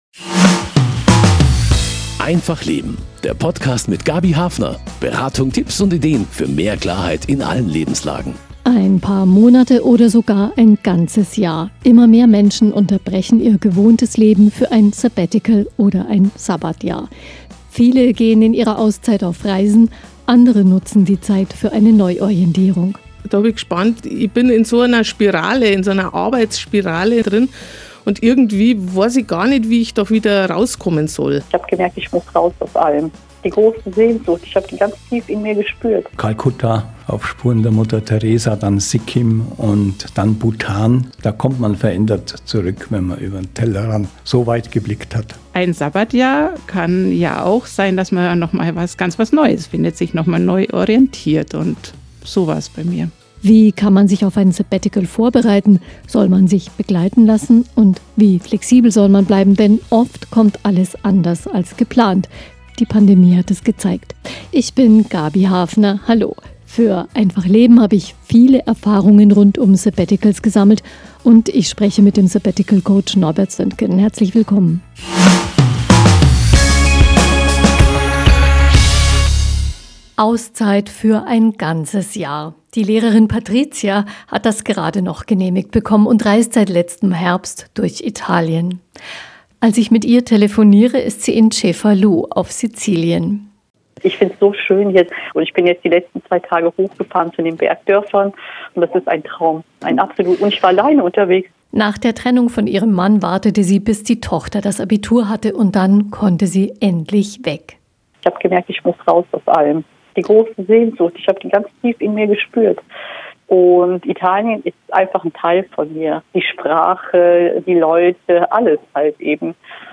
Tipps und Erfahrungen im Radiointerview